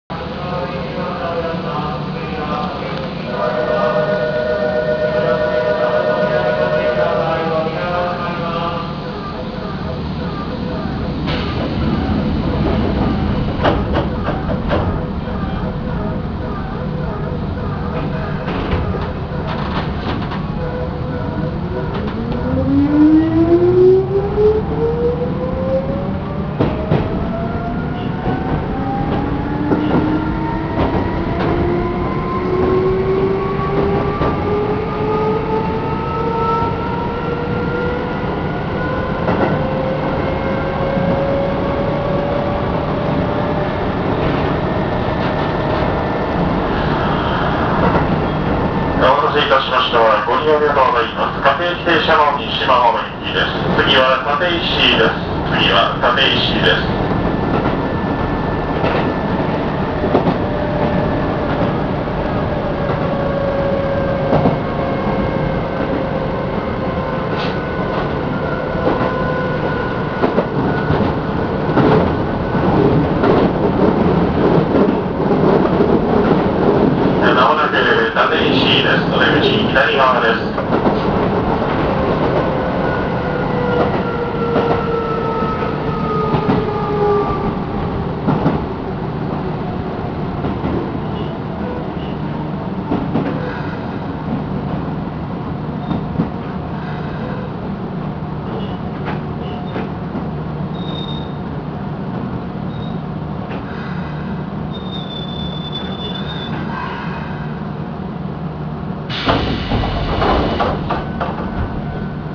・3400形走行音
【押上線】青砥〜立石（1分52秒：611KB）
旧AE形の音そのままになります。基本的には3600形もほぼ同じ音です。起動時にかなり爆音になるのが特徴。この点については3600形よりも大分激しいように感じます。